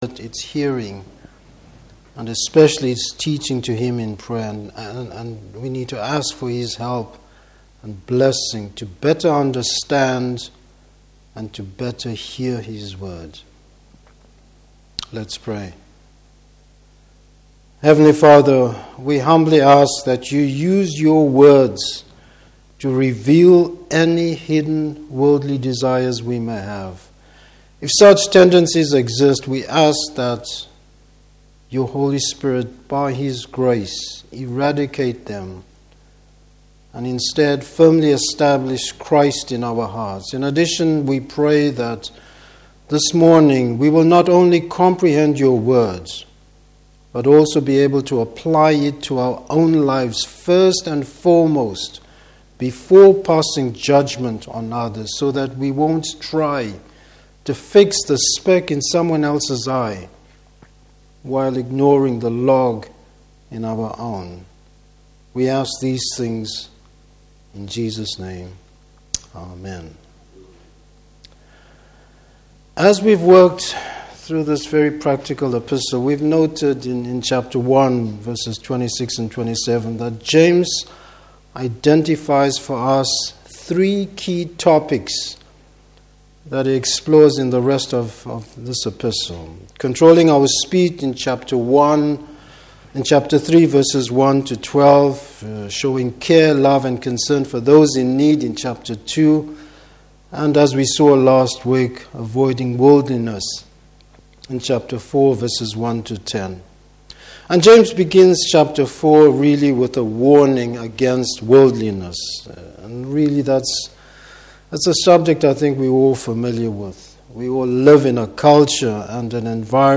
Service Type: Morning Service Keeping Christian fellowship and living in the light of God’s plan.